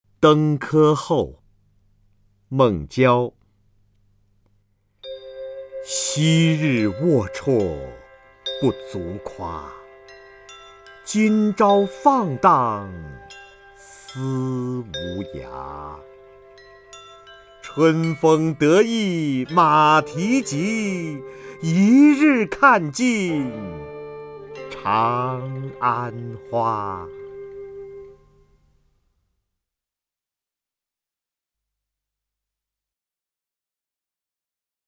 瞿弦和朗诵：《登科后》(（唐）孟郊) （唐）孟郊 名家朗诵欣赏瞿弦和 语文PLUS